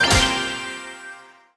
brawl_button_01.wav